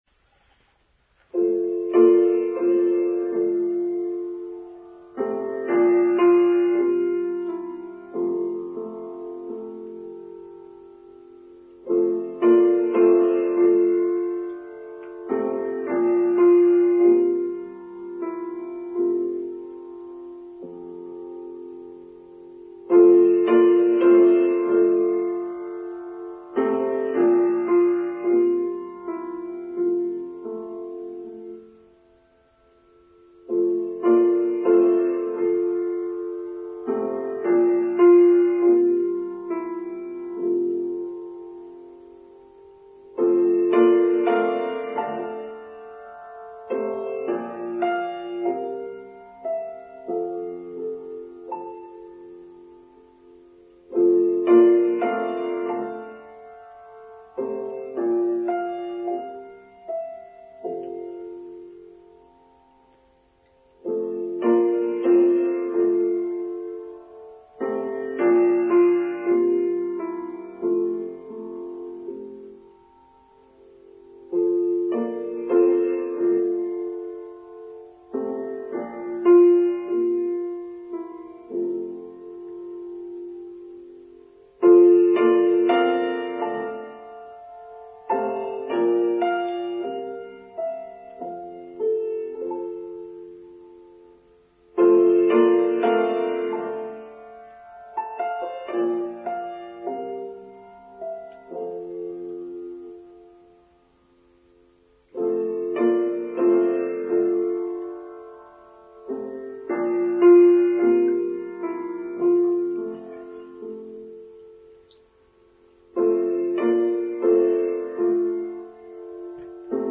Human Rights Day: Online Service for Sunday 11th December 2022